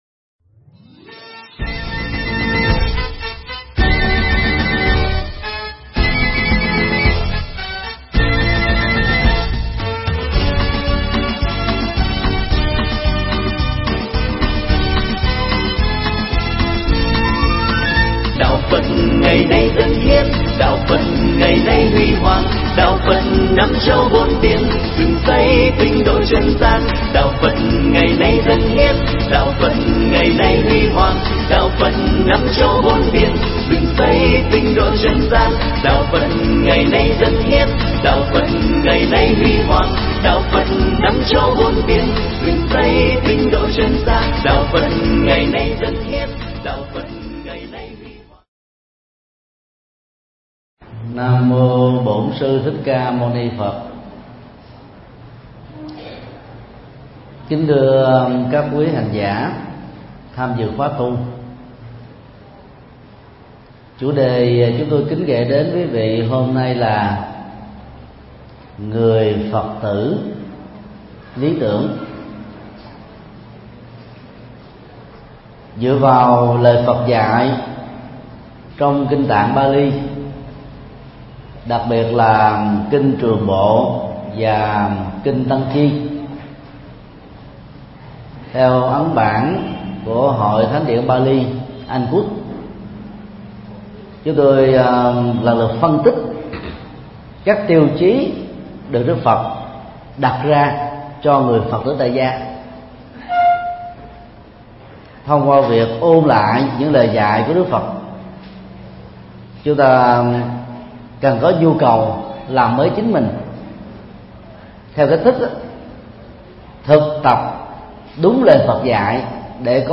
Mp3 Pháp thoại Người Phật tử lý tưởng
Giảng tại chùa Ấn Quang, Quận 10, TP.HCM